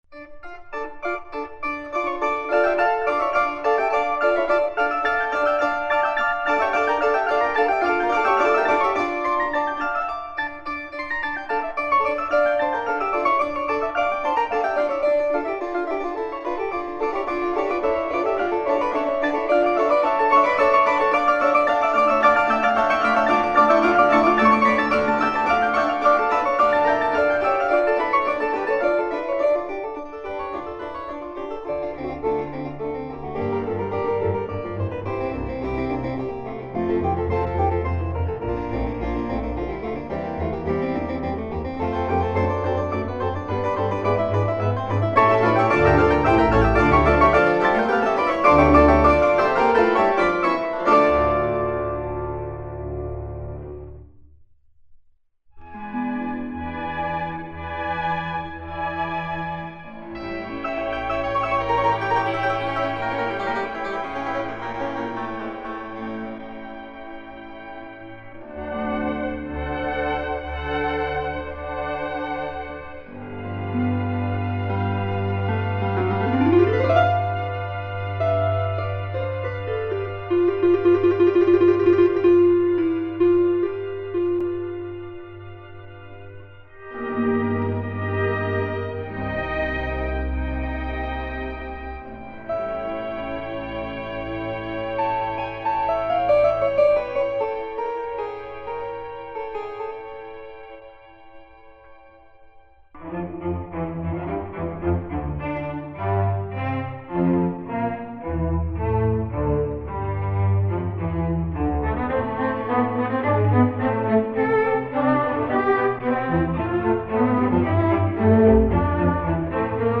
Concerto for 4 Harpsichords & Orchestra